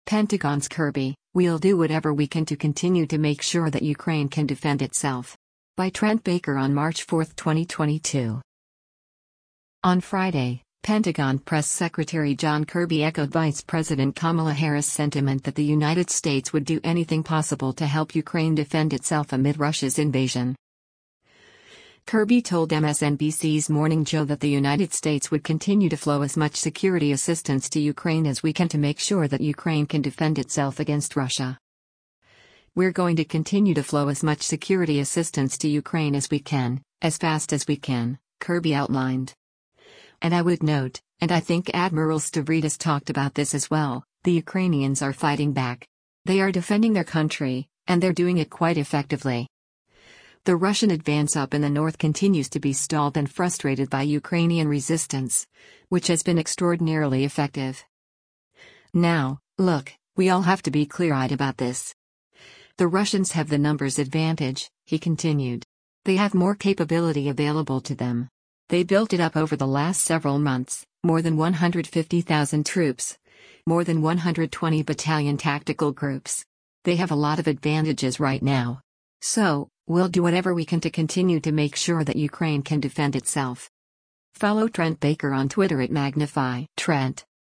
Kirby told MSNBC’s “Morning Joe” that the United States would “continue to flow as much security assistance to Ukraine as we can” to make sure that “Ukraine can defend itself” against Russia.